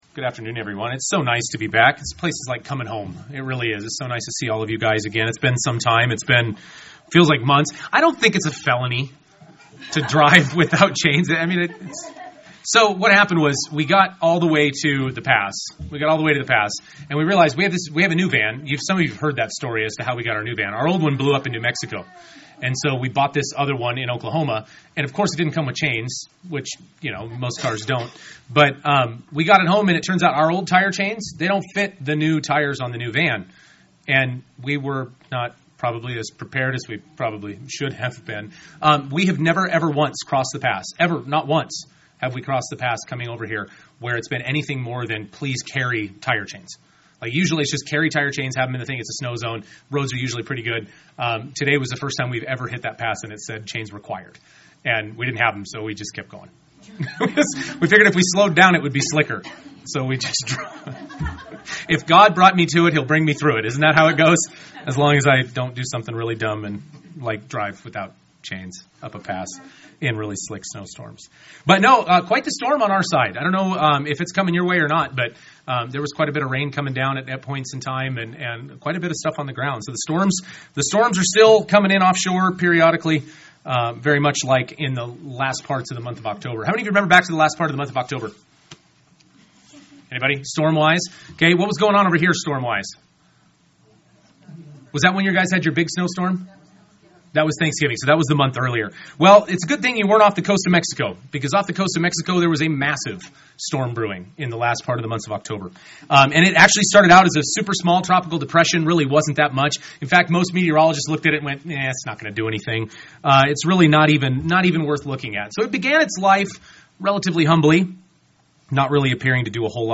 UCG Sermon Trials Transcript This transcript was generated by AI and may contain errors.